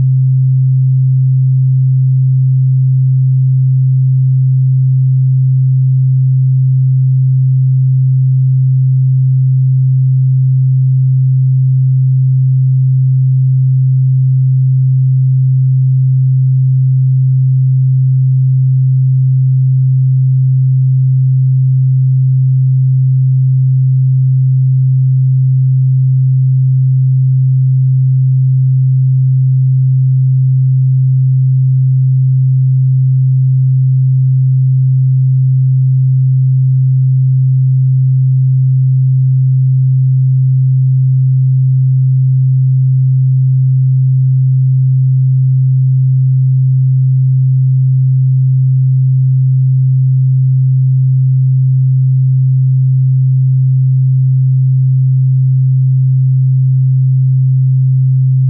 70Hz-Sine-Test-Tone.wav